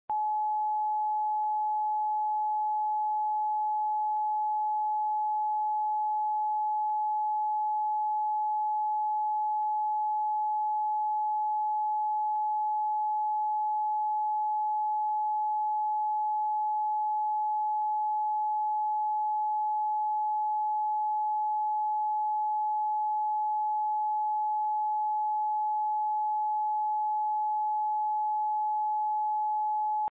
Frecuencia 852 Hz 🔊 ⚠Te sound effects free download